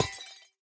25w18a / assets / minecraft / sounds / block / copper_bulb / break1.ogg
break1.ogg